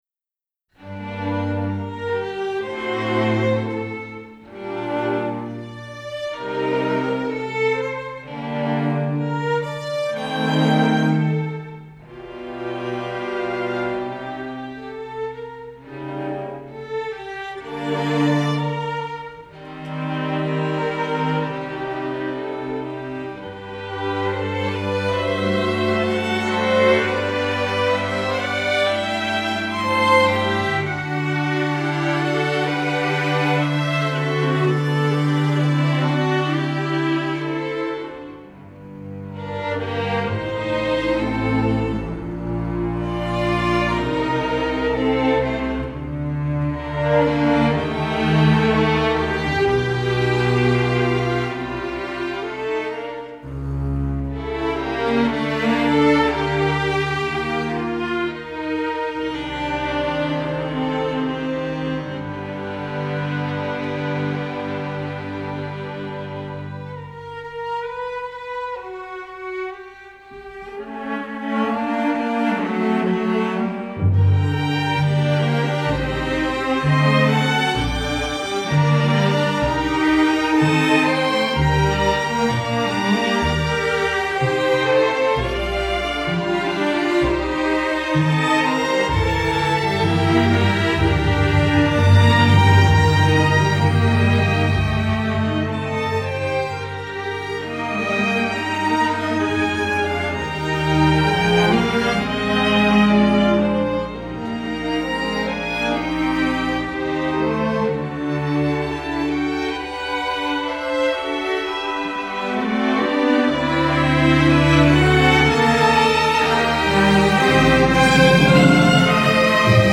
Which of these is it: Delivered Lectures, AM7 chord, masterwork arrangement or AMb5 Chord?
masterwork arrangement